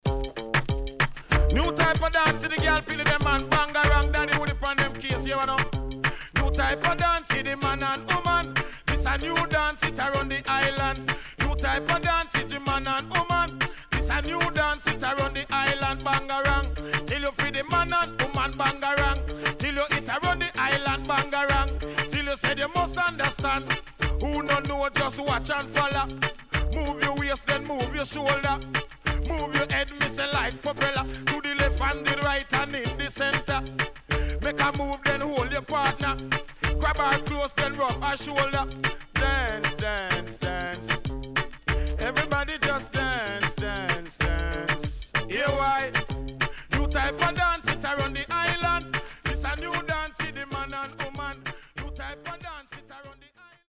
HOME > LP [DANCEHALL]
SIDE A:少しチリノイズ入りますが良好です。